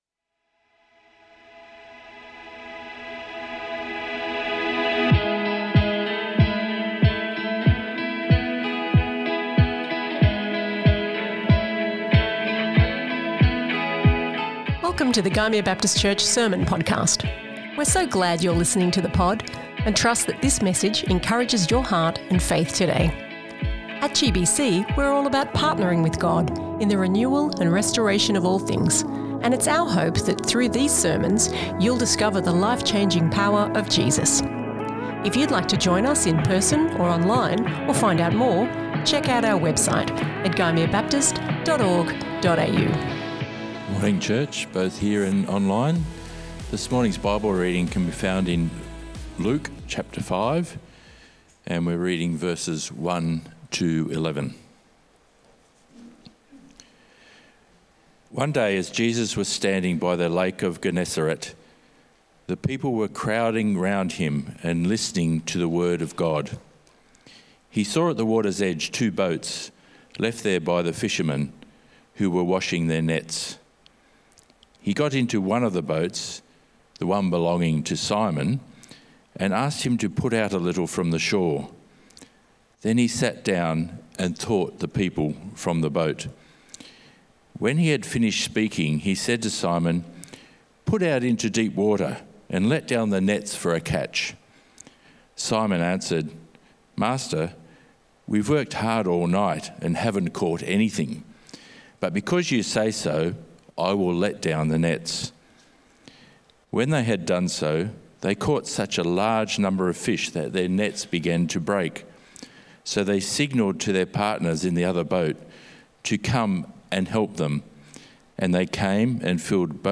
This message from our Sunday church service is part of the resources we provide as we seek to see lives changed by Jesus.